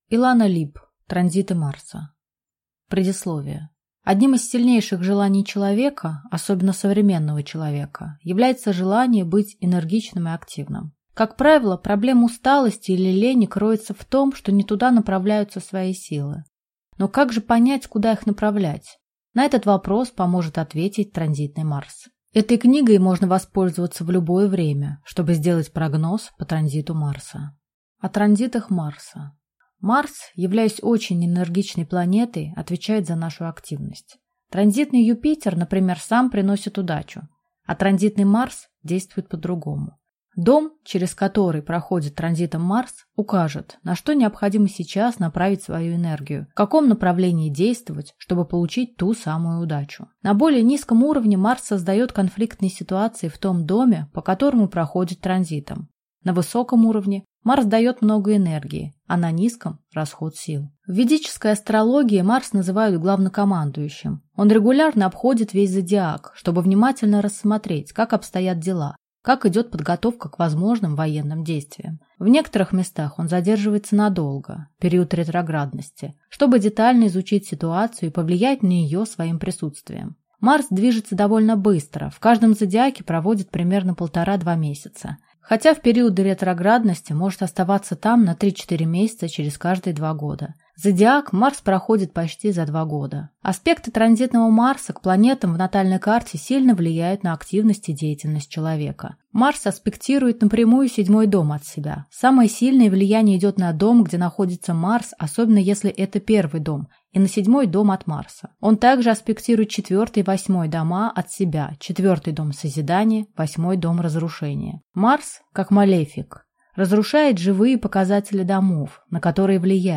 Аудиокнига Транзиты Марса | Библиотека аудиокниг